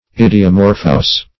Search Result for " idiomorphous" : The Collaborative International Dictionary of English v.0.48: Idiomorphous \Id`i*o*morph"ous\, a. [Gr.